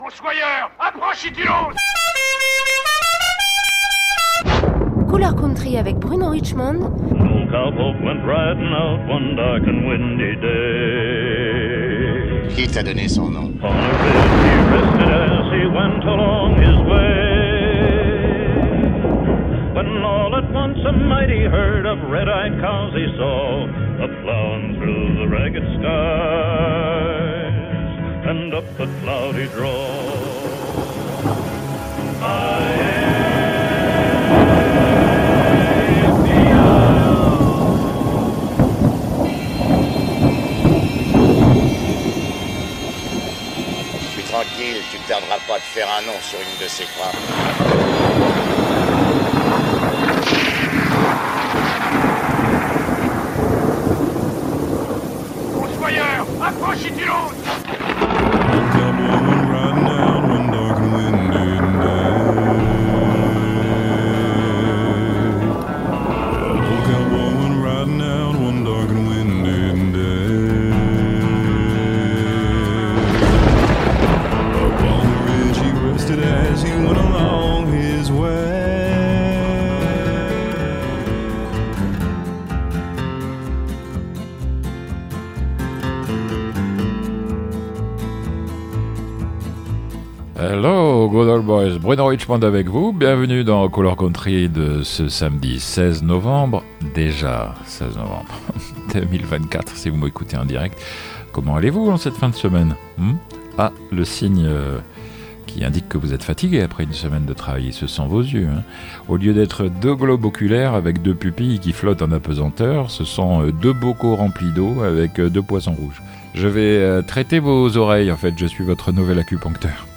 country traditionnelle